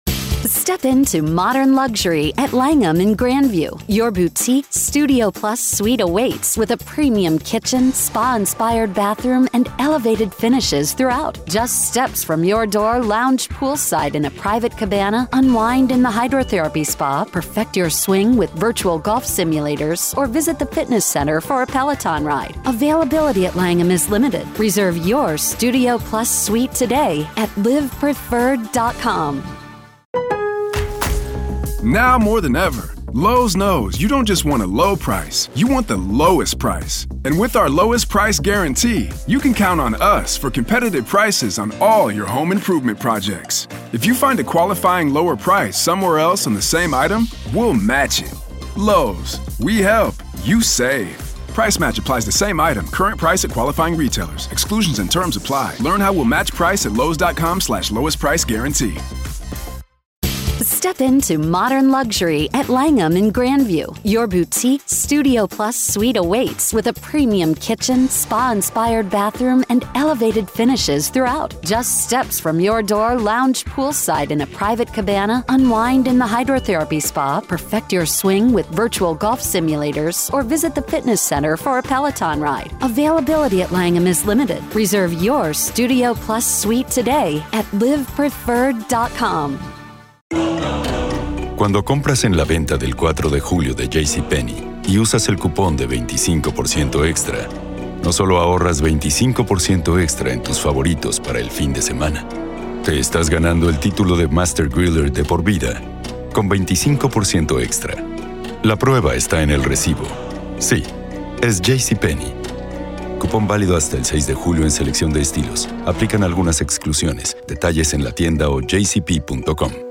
raw courtroom testimony